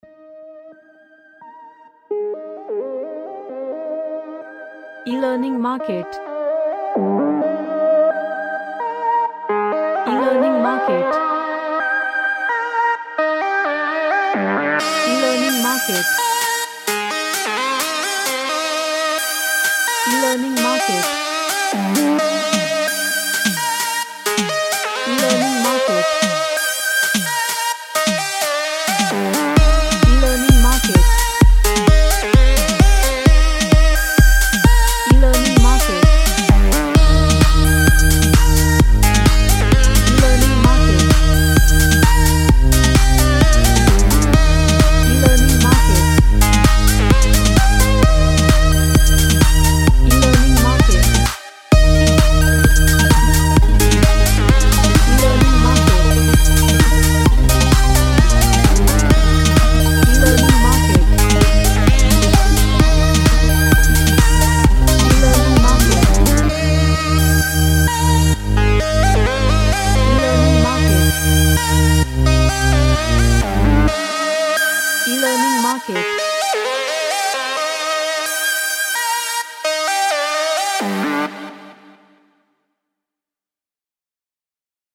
A dance track with trance vibe
Dance